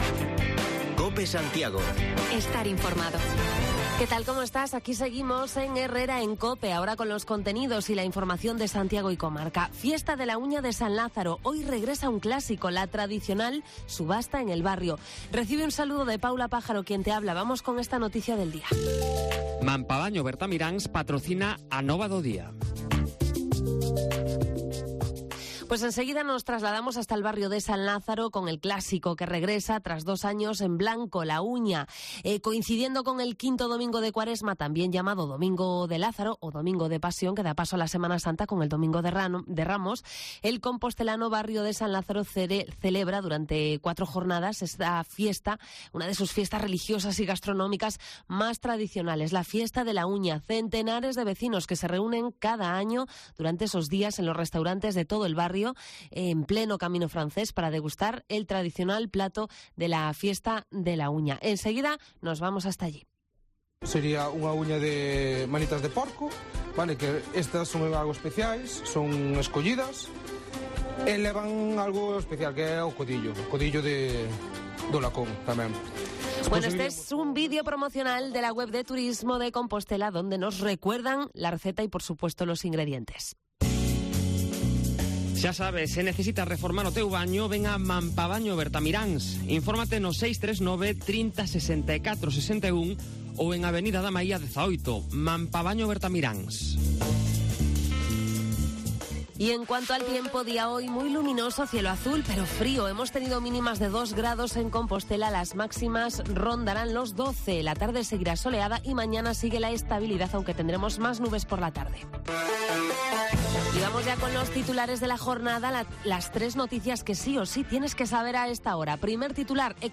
Redacción digital Madrid - Publicado el 04 abr 2022, 14:39 - Actualizado 18 mar 2023, 12:19 1 min lectura Descargar Facebook Twitter Whatsapp Telegram Enviar por email Copiar enlace Primer lunes de abril, lunes de fiesta en el barrio de San Lázaro, que dos años después, recupera la subasta de las uñas. Te lo contamos desde allí